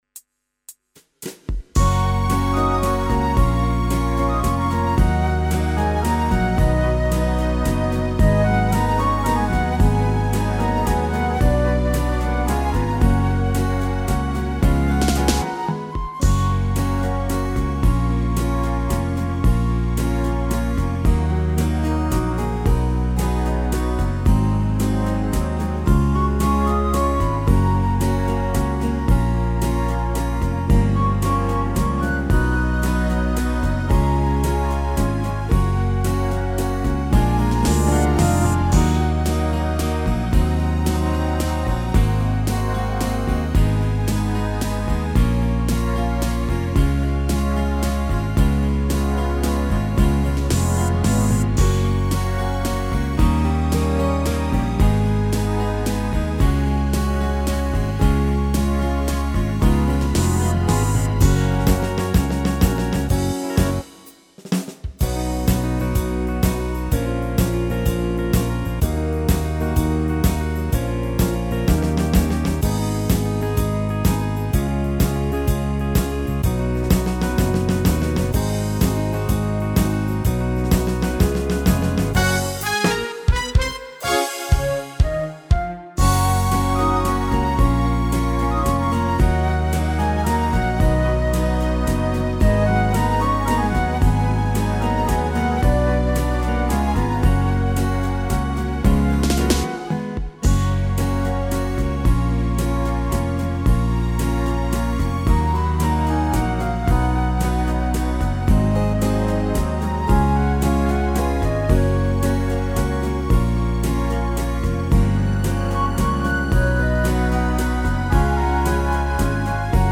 Tone Nam (F# hoặc G)